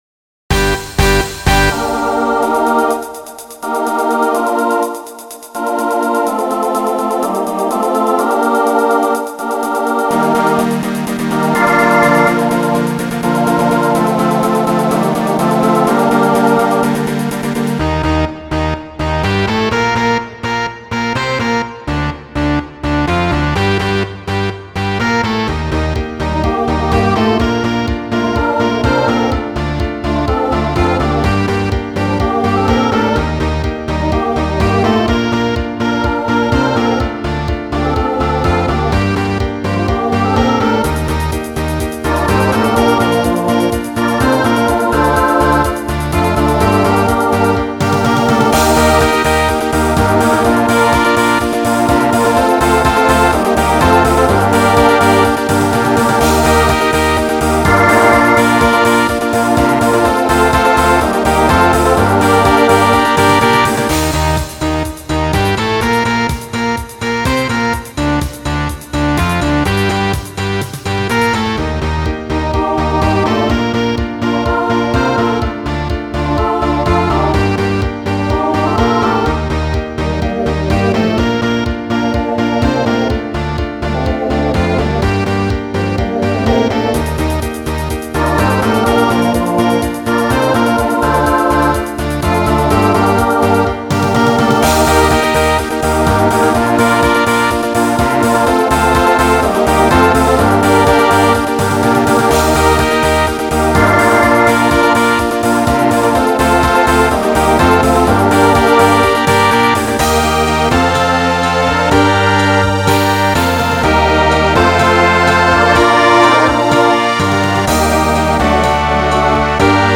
SATB Instrumental combo
Broadway/Film , Rock